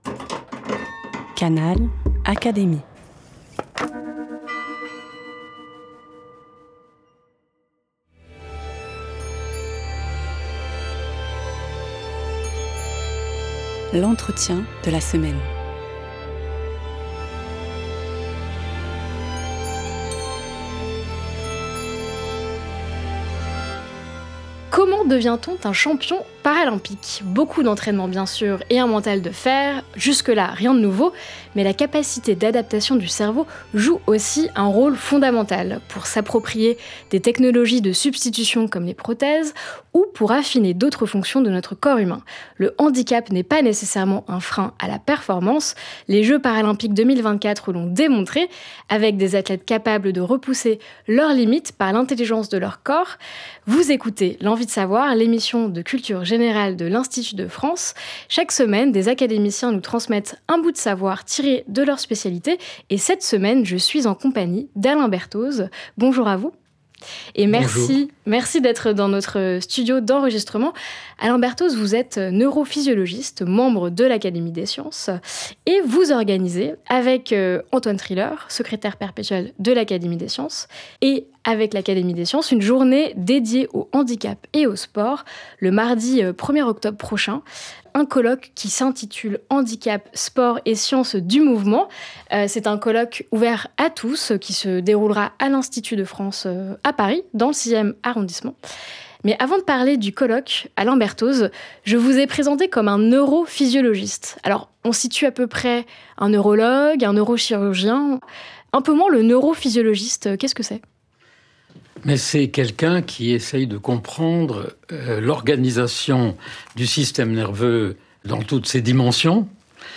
Le neurophysiologiste Alain Berthoz, membre de l’Académie des sciences, présente au micro de l’Envie de savoir la formidable capacité d'adaptation du corps humain.